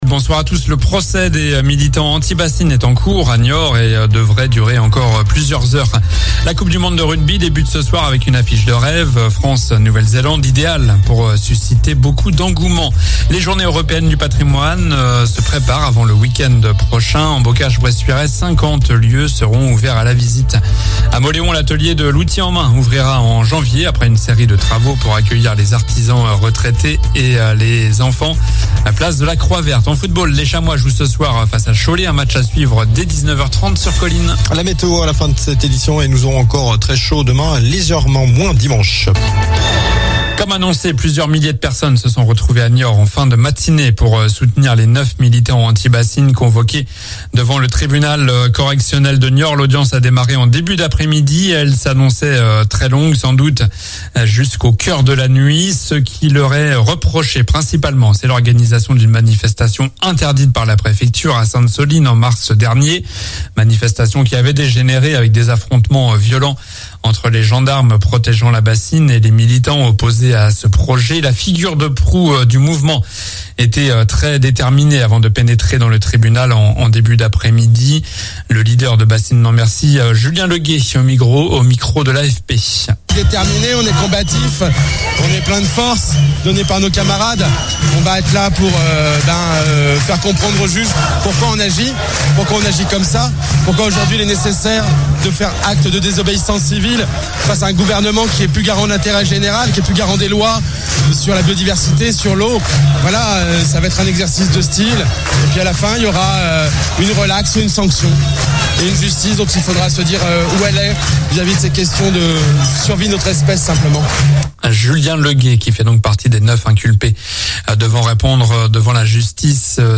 Journal du vendredi 8 septembre (soir)